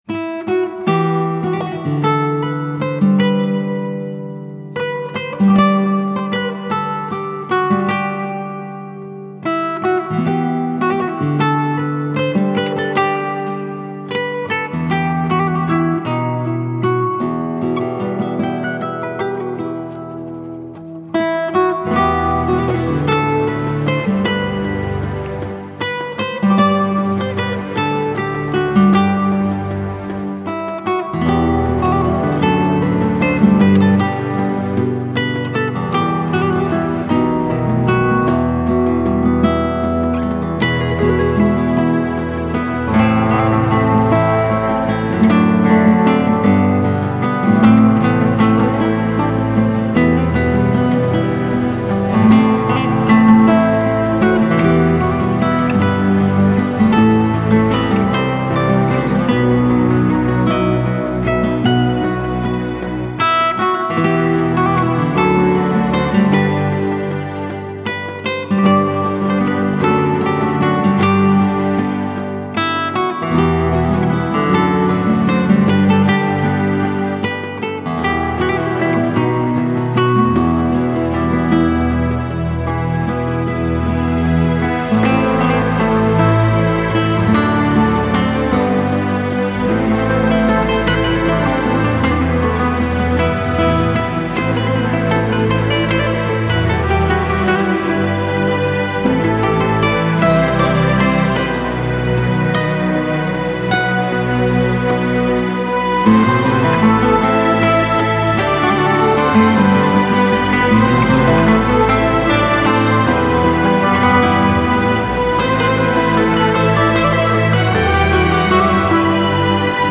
Жанр: фолк-рок
Genre: Folk, Rock